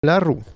prononciation ↘ explication La rue, Ruta graveolens, c’est une plante qui vient du sud-ouest de l’Europe, utilisée depuis l’Empire romain.